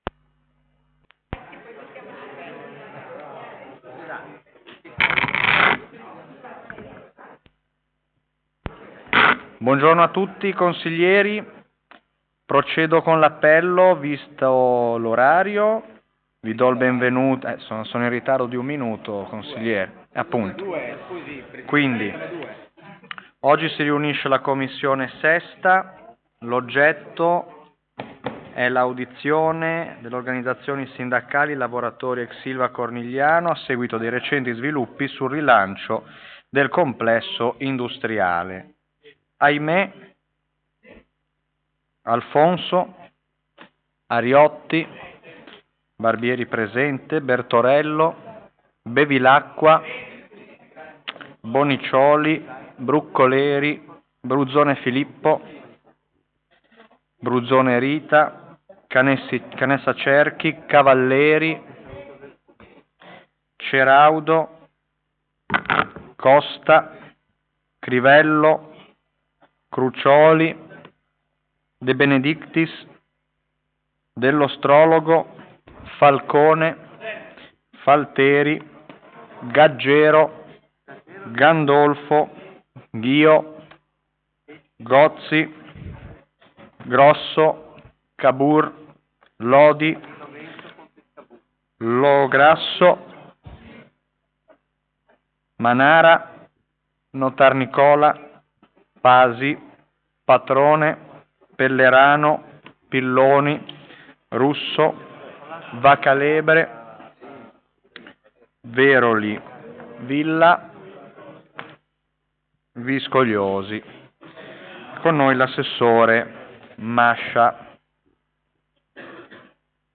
Luogo: presso la sala consiliare di Palazzo Tursi - Albini
Sono previste audizioni.